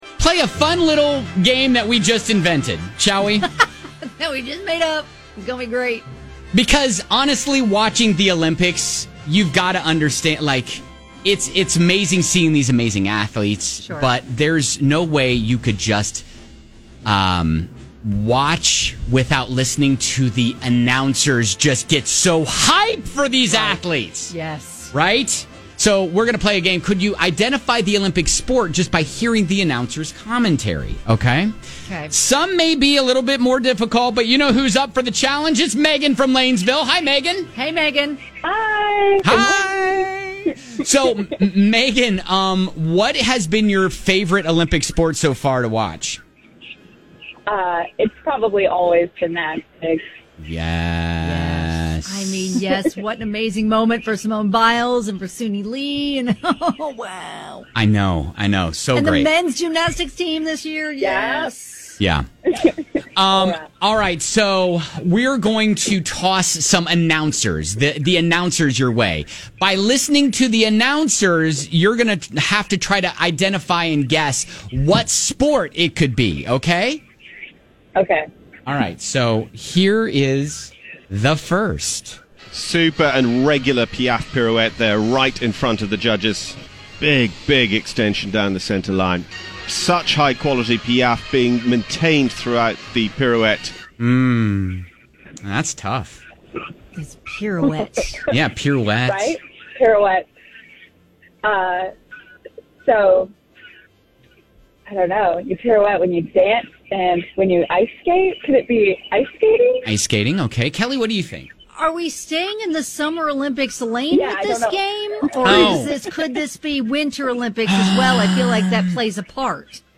Can You Identify the Olympic Sport Just by Hearing the Announcer's Commentary?